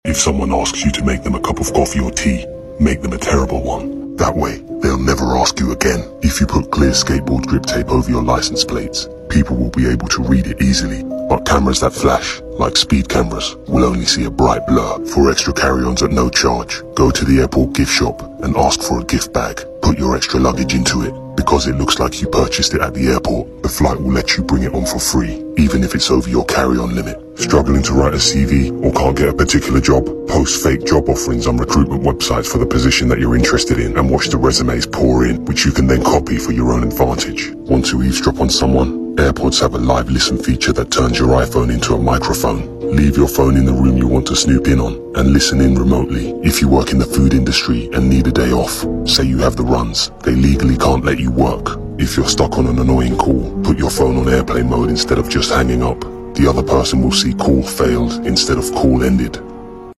Spooky, quiet, scary atmosphere piano songs Unethical Life Hacks Disclaimer!